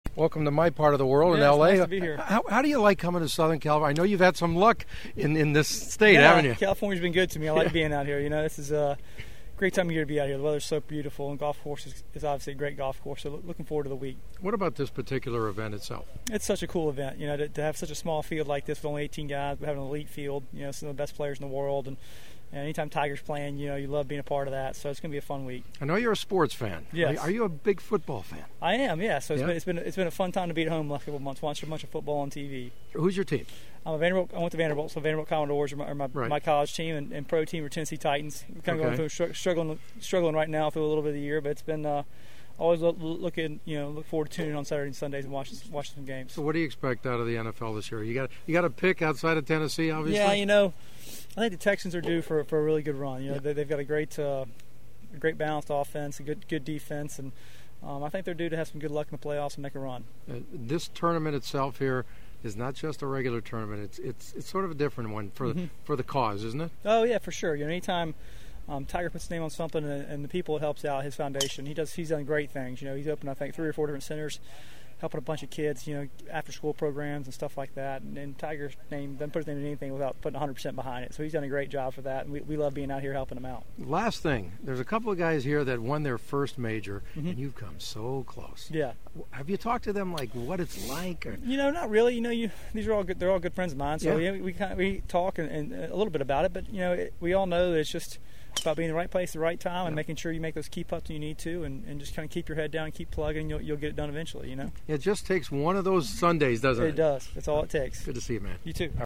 Then this past December at the Tiger Woods hosted World Challenge event in Thousand Oaks. I chatted with Snedeker for a few minutes on the driving range where we talked about playing in So-Cal and how he sees what it will take to win his first major?